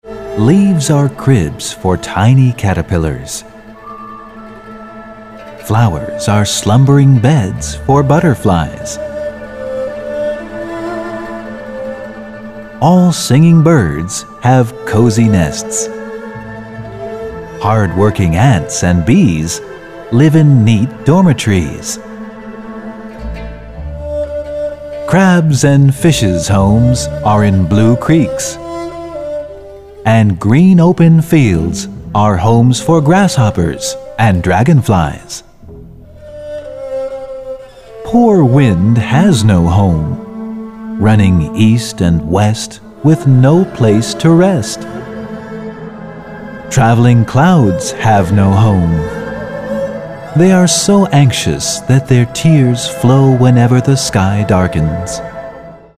【朗讀版】